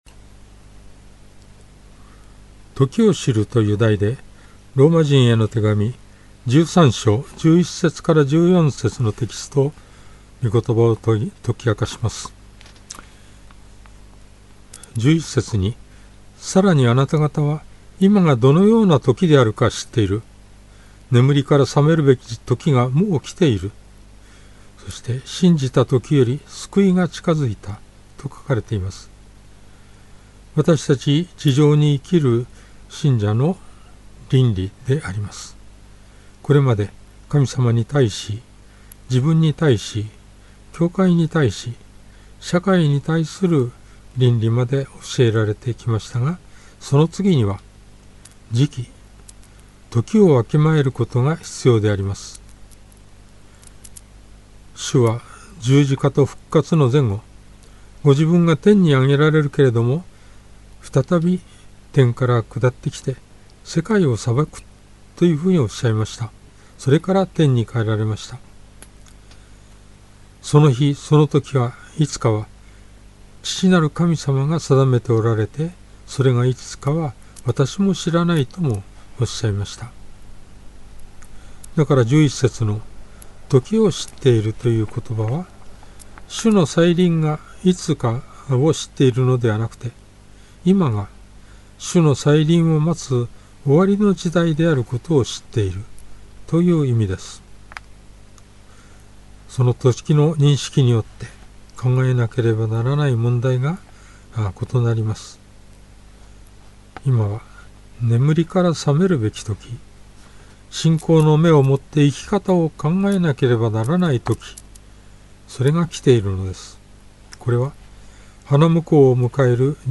Sermon
主日礼拝
♪ 事前録音分